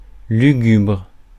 Ääntäminen
IPA: /ly.ɡybʁ/